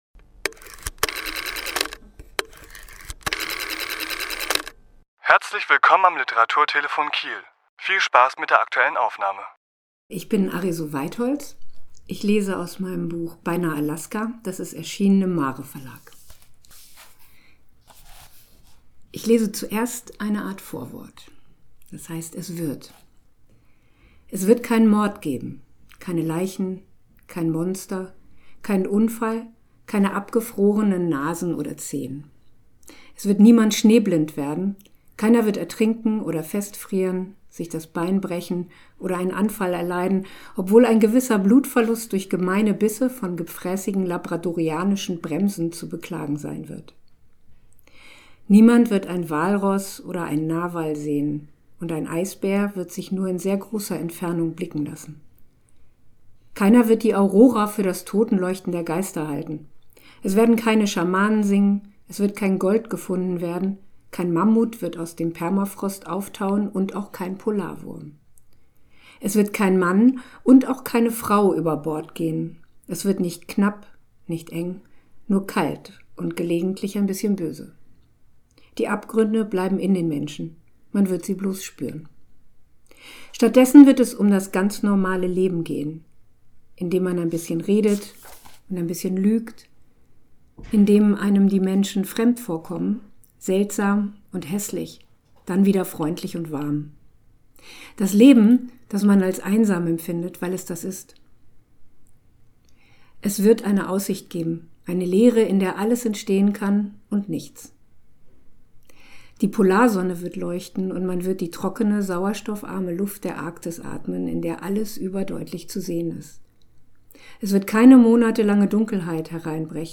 Autor*innen lesen aus ihren Werken
Die Aufnahme entstand im Rahmen einer Lesung am 31.3.2022 im Literaturhaus Schleswig-Holstein.